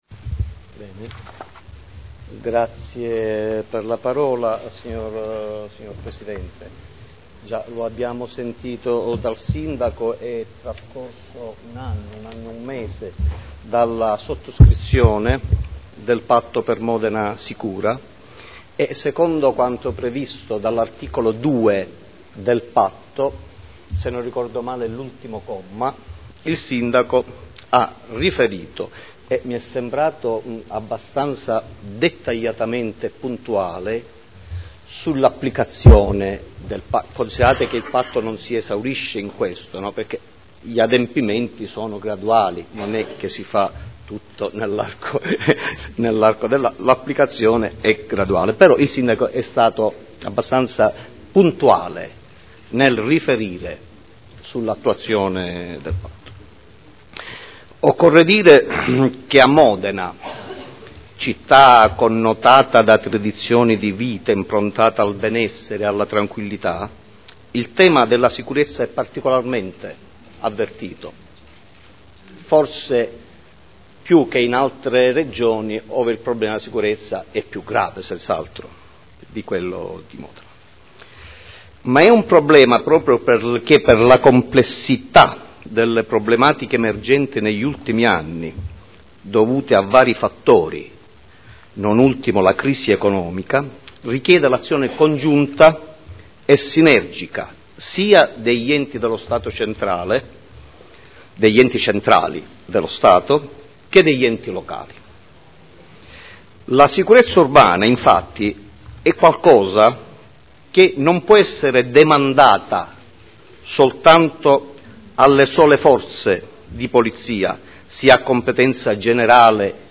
Seduta del 17/05/2012. Lo stato della sicurezza della città e l’attività delle Forze di Polizia